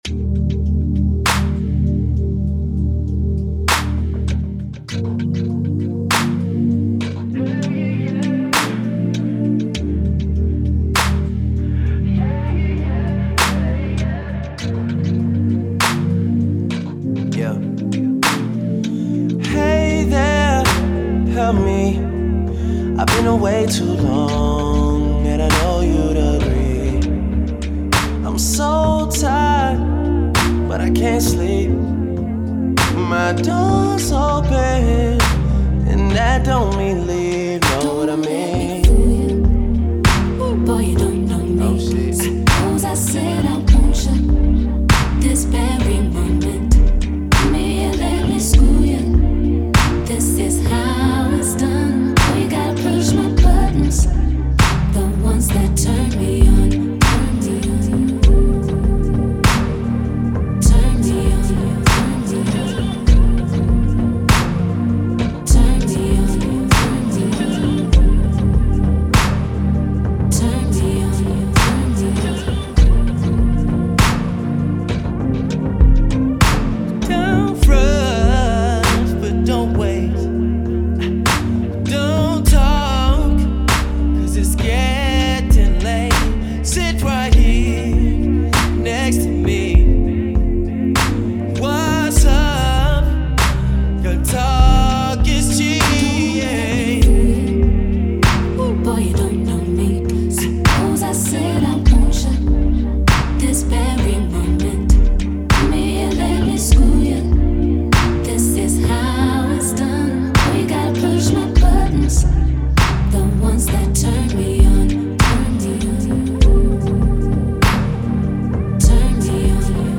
SINGLESHIP-HOP/RAPR&B/SOUL